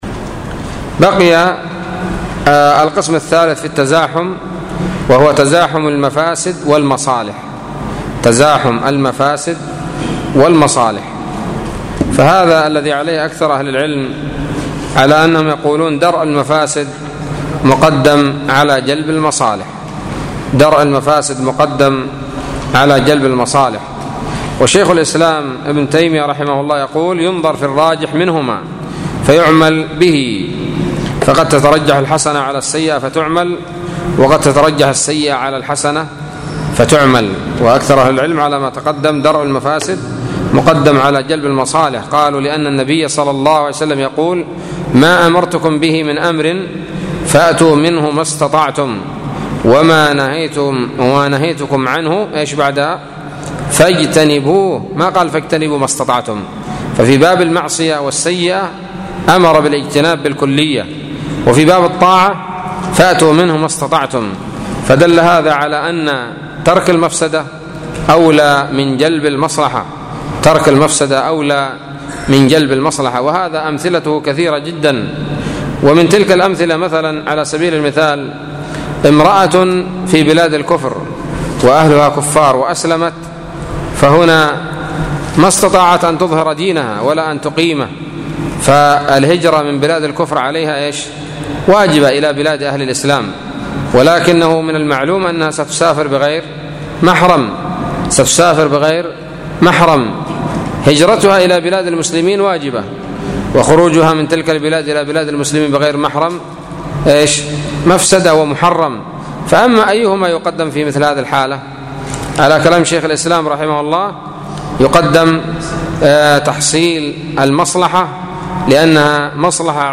الدرس الحادي عشر من شرح منظومة القواعد الفقهية للشيخ عبد الرحمن السعدي رحمه الله